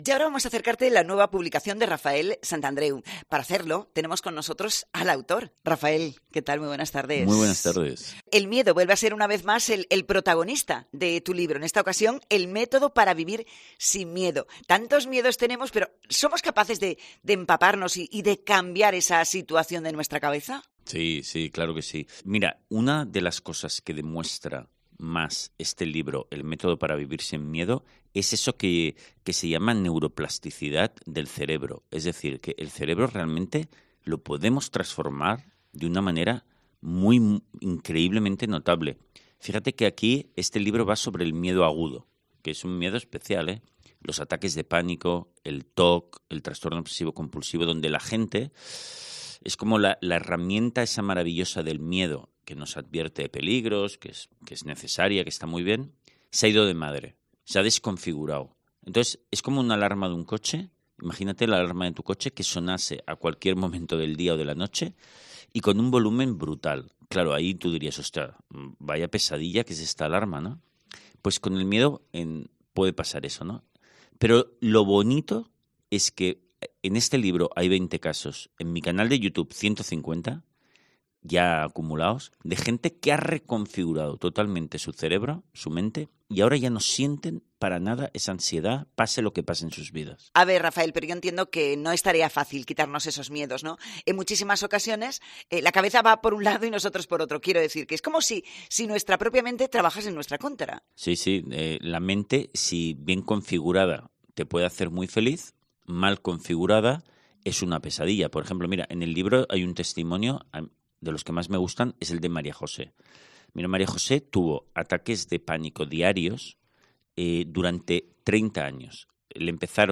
Entrevista con Rafael Santandreu, autor de "El método para vivir sin miedo"